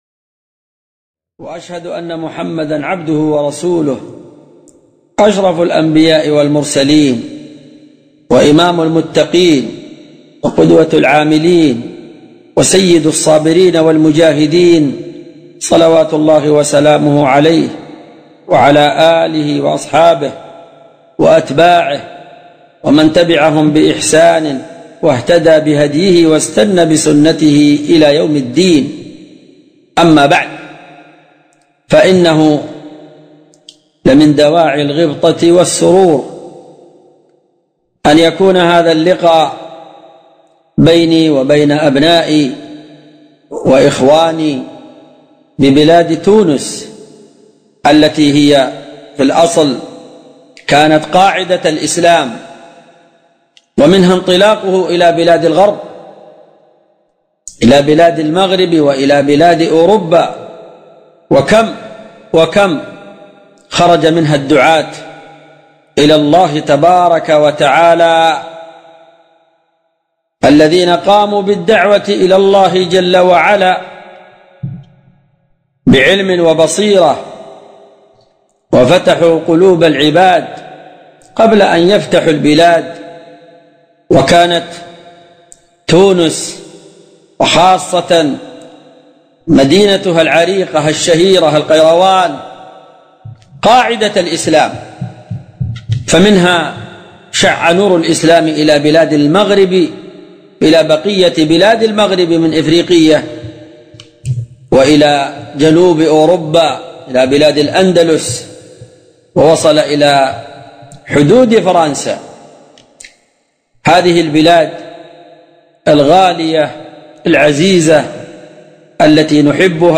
(محاضرة ألقاها عبر الهاتف يوم السبت 1 ذو الحجة عام 1437هـ للإخوة في مدينة بومهل بتونس).